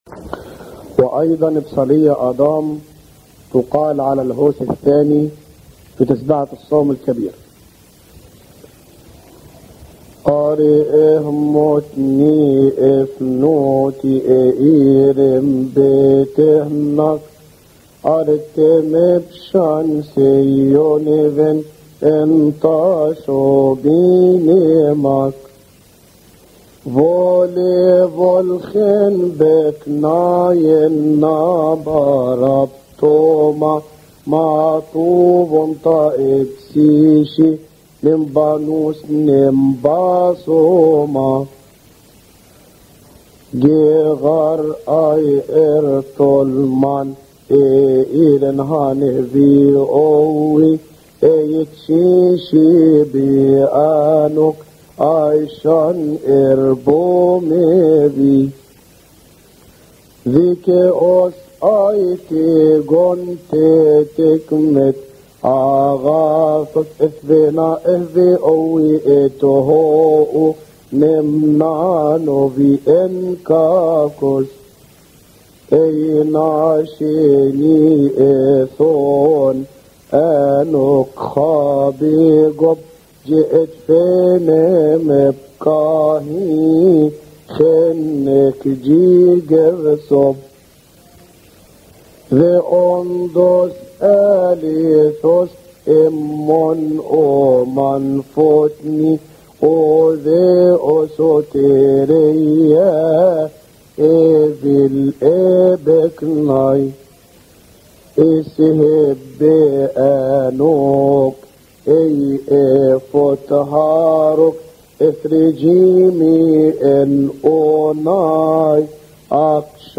ابصالية آدام على الهوس الثاني